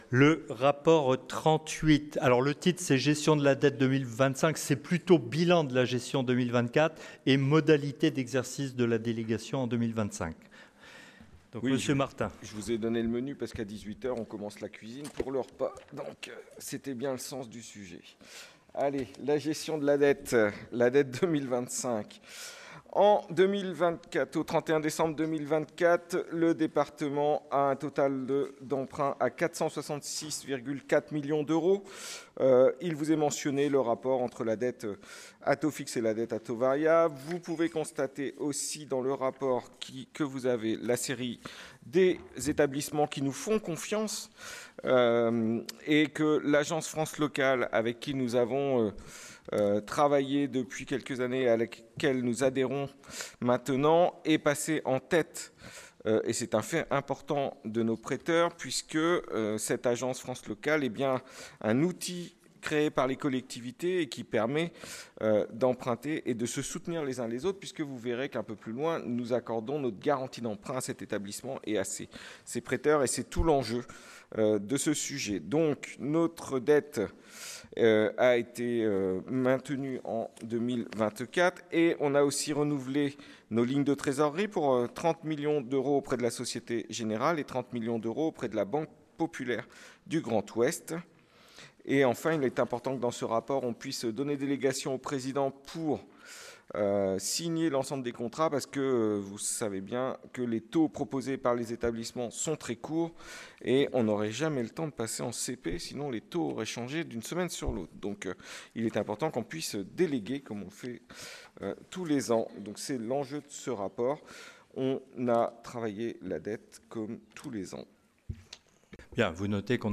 • Assemblée départementale du 19/03/25